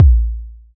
Deep Steel Kick Drum Sound A# Key 105.wav
Royality free steel kick drum sample tuned to the A# note. Loudest frequency: 95Hz
deep-steel-kick-drum-sound-a-sharp-key-105-VwT.mp3